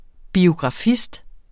Udtale [ biogʁɑˈfisd ]